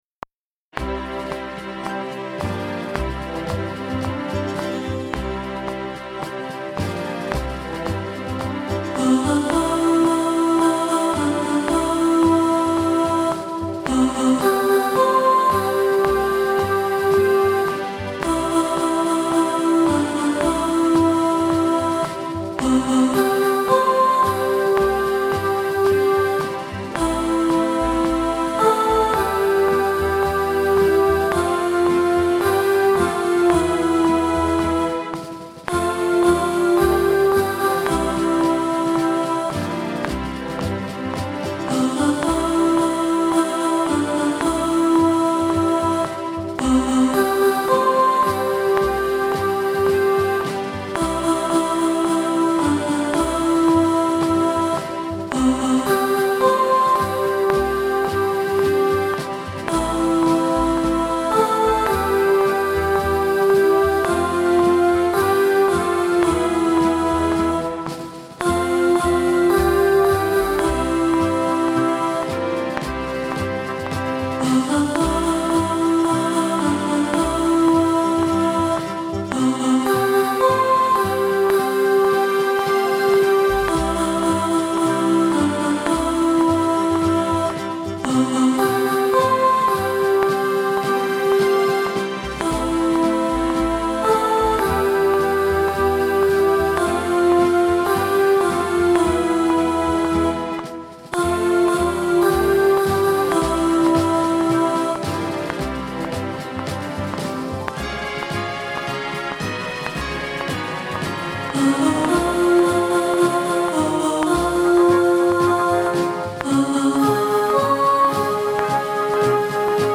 Do-You-Hear-What-I-Hear-Alto.mp3